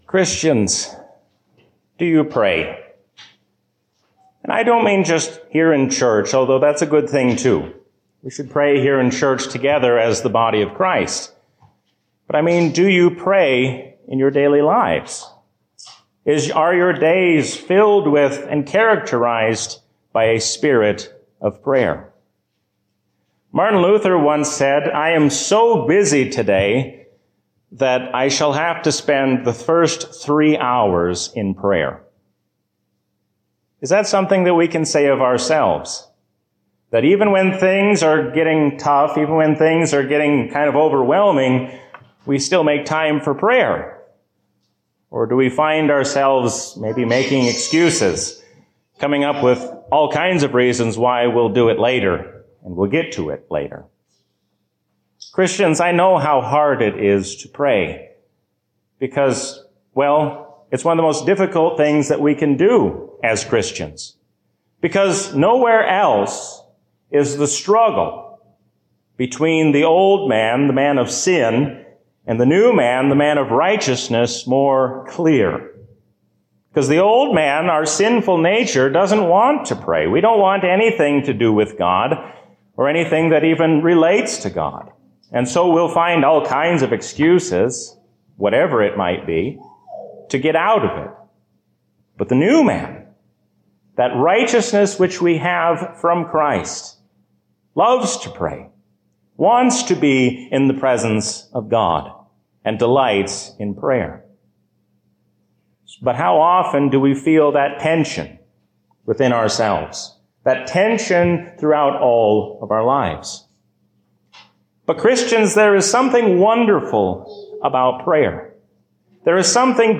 A sermon from the season "Trinity 2023." Pray that the Lord would bring an end to evil, and He will answer as He has promised.